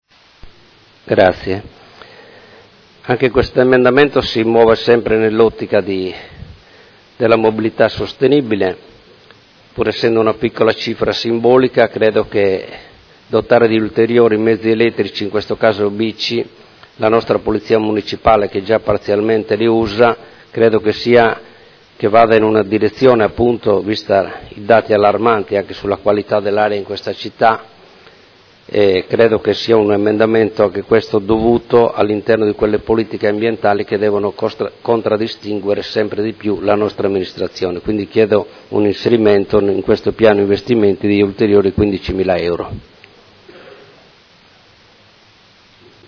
Marco Cugusi — Sito Audio Consiglio Comunale
Seduta del 26 gennaio. Bilancio preventivo: emendamento n°9868